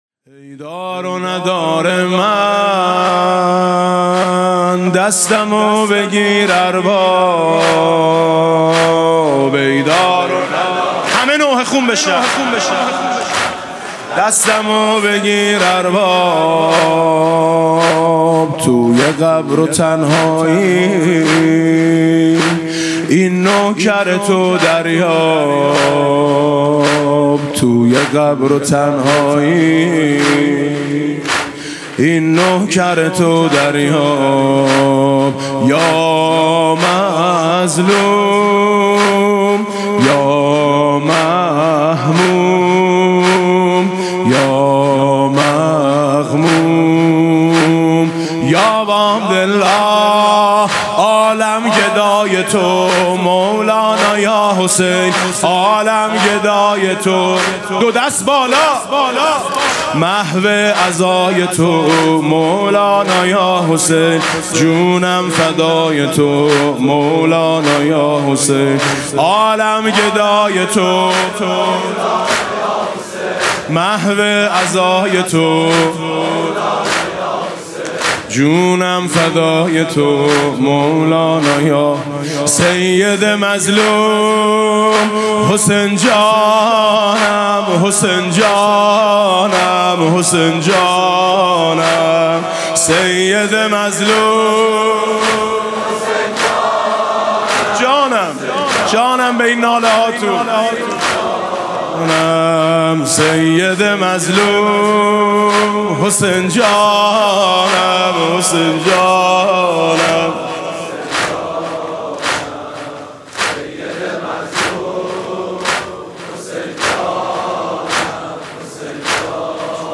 مسیر پیاده روی نجف تا کربلا [عمود ۹۰۹]
مناسبت: ایام پیاده روی اربعین حسینی
با نوای: حاج میثم مطیعی
ای دار و ندار من، دستمو بگیر ارباب (زمینه)